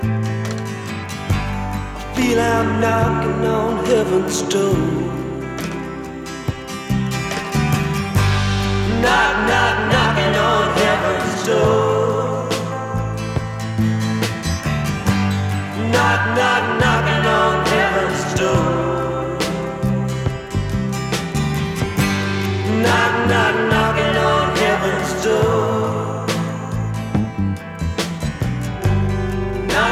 Рингтоны
Жанр: Музыка из фильмов / Саундтреки